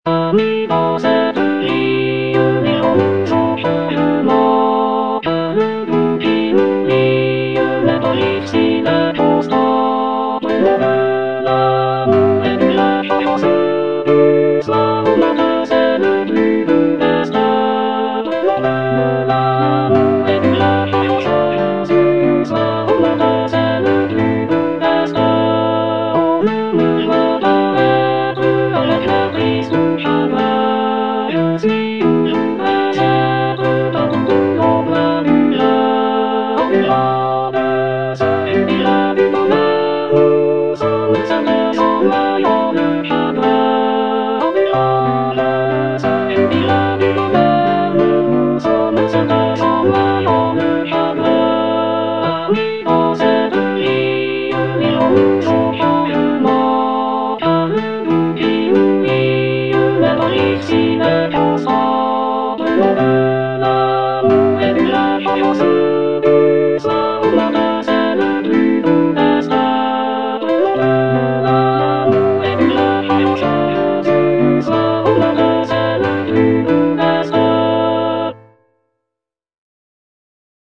Alto (Emphasised voice and other voices)